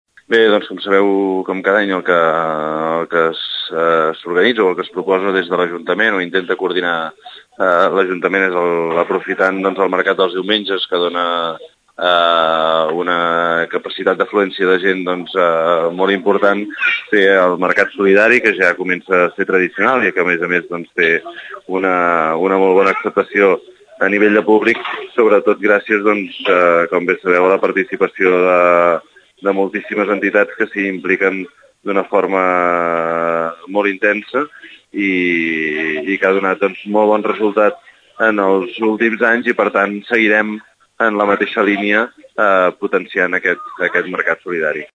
Escoltem el coordinador del govern, Josep Llorens.